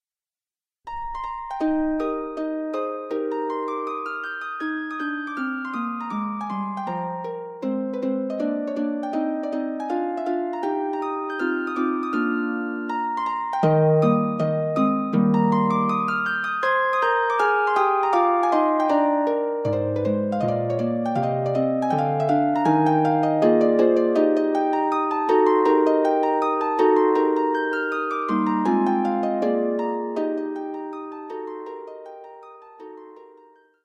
for solo pedal harp